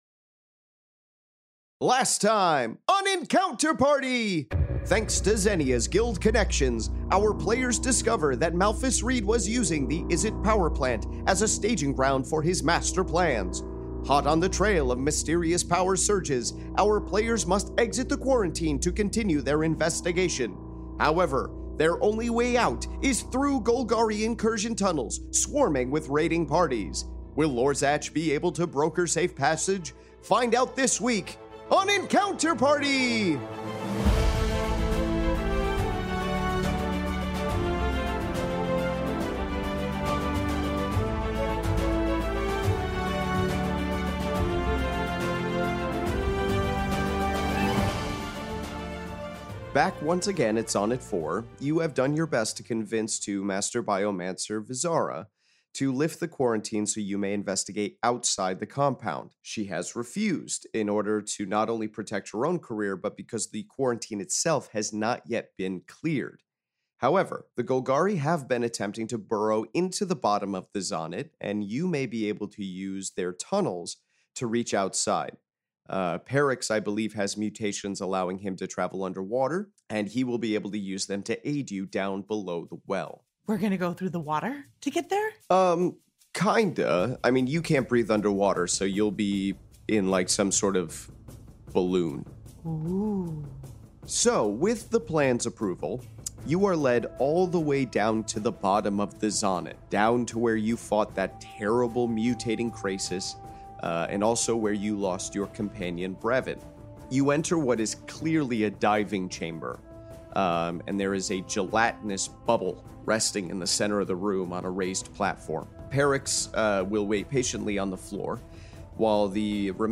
Fantasy Mystery Audio Adventure governed by the rules of Dungeons & Dragons
five actors and comedians